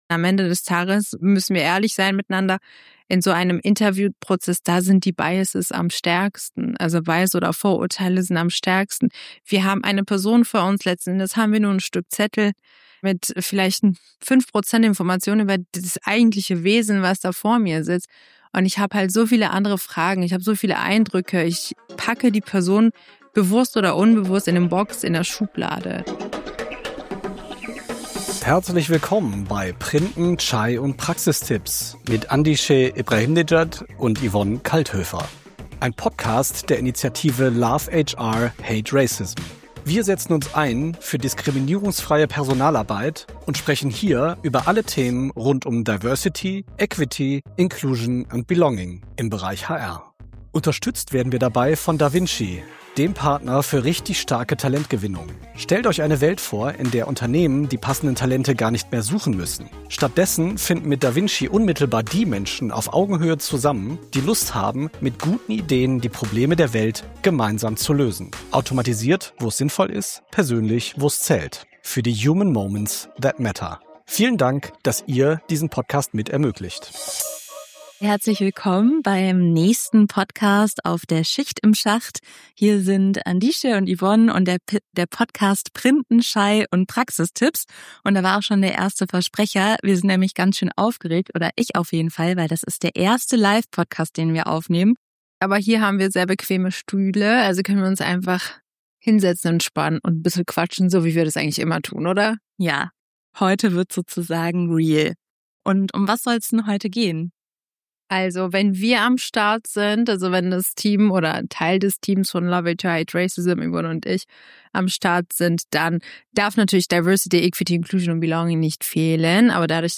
Man, waren wir nervös! Wir diskutieren, wie Bewerber:innen herausfinden können, ob ein Unternehmen seine Versprechen zu Integration und Diversität tatsächlich lebt. Welche Fragen im Vorstellungsgespräch sind erlaubt und sinnvoll, um einen authentischen Eindruck zu gewinnen?